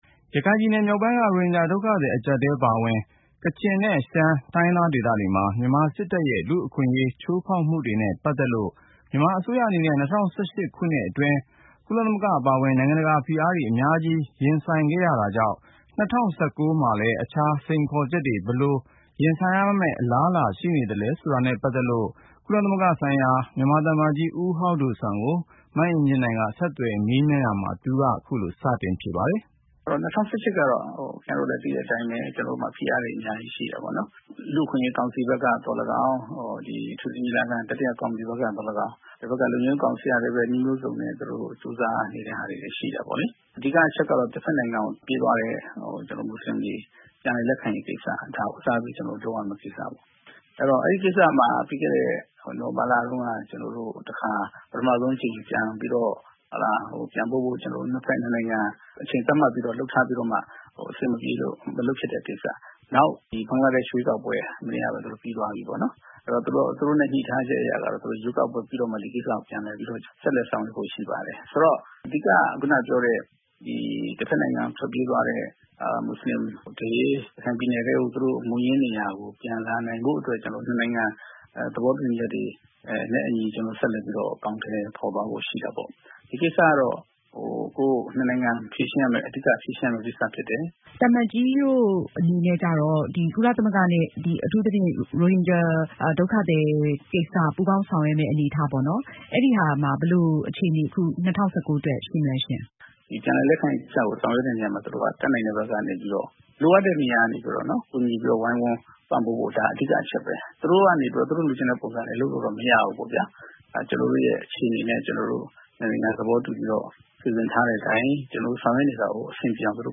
Interview with Hau Do Suan, Permanent Representative of the Republic of the Union of Myanmar to the UN